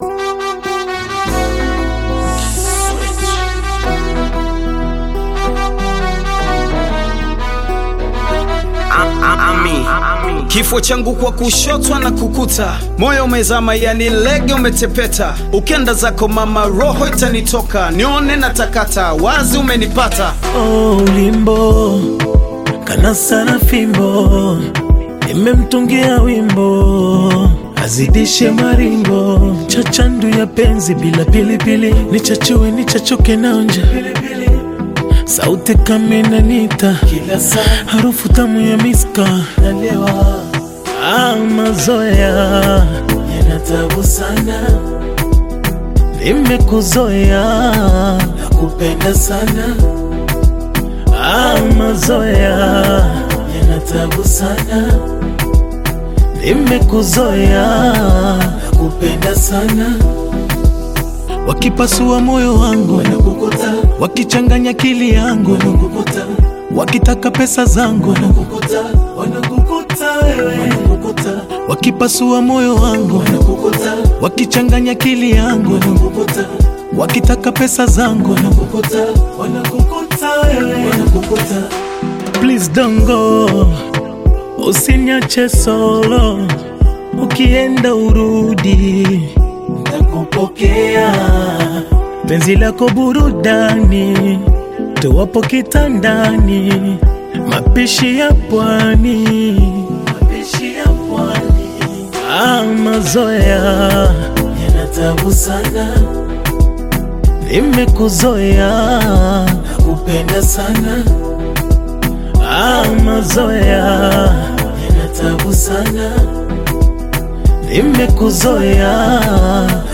Tanzanian Bongo Flava artist and singer
Bongo Flava